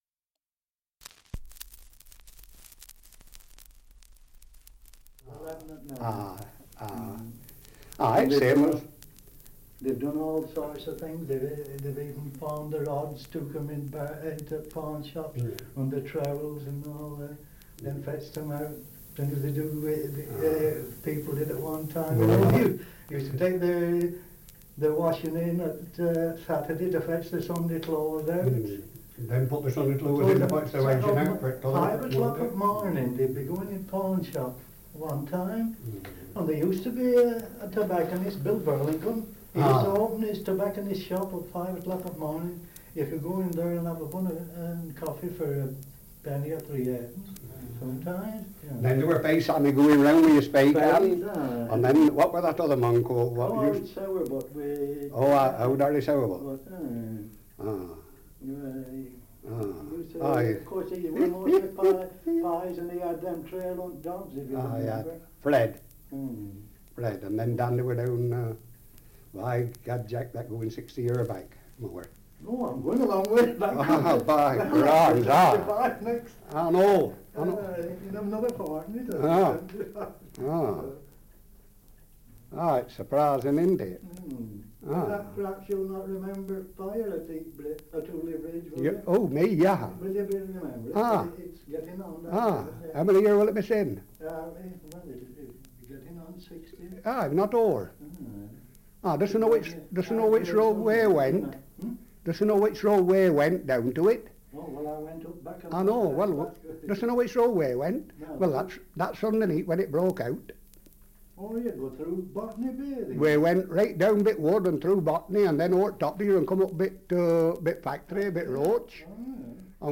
Dialect recording in Heywood, Lancashire
78 r.p.m., cellulose nitrate on aluminium